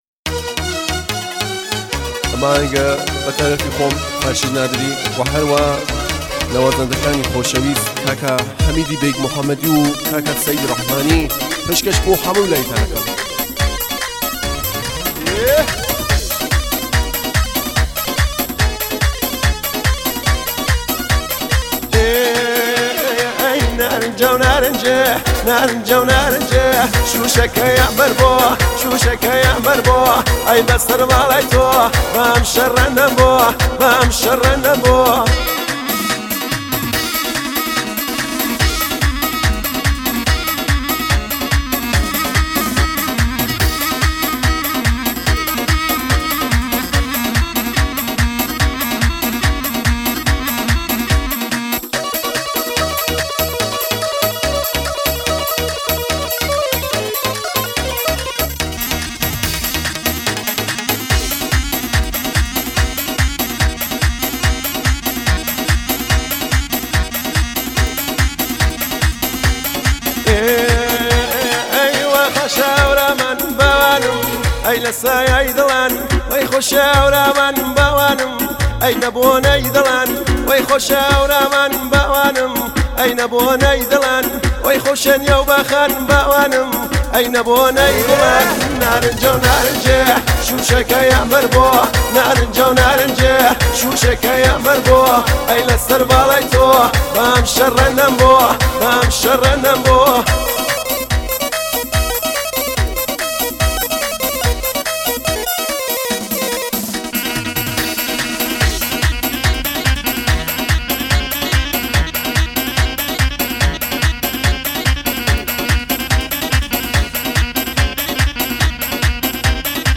کردی